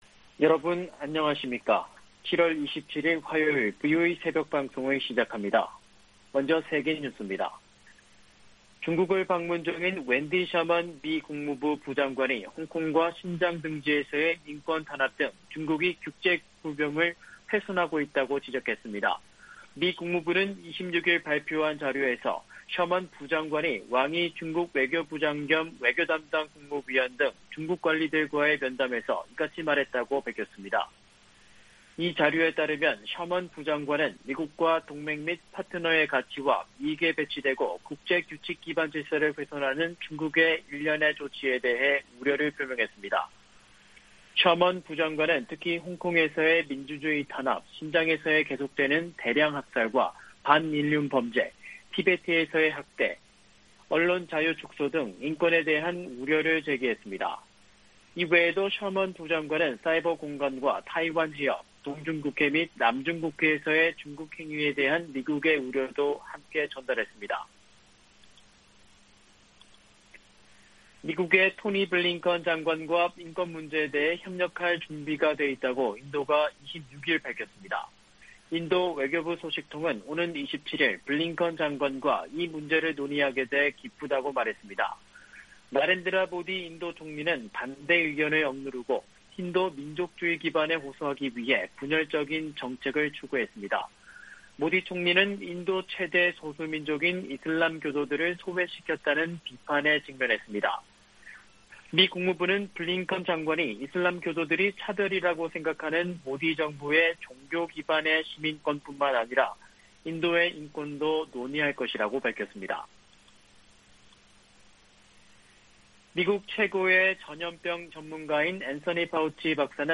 세계 뉴스와 함께 미국의 모든 것을 소개하는 '생방송 여기는 워싱턴입니다', 2021년 7월 27일 아침 방송입니다. '지구촌 오늘'에서는 중국 톈진에서 미국과 중국 간 고위급 대화가 열린 소식, '아메리카 나우'에서는 코로나 대응에 "잘못된 방향으로 가고 있다"고 앤서니 파우치 박사가 경고한 소식 전해드립니다.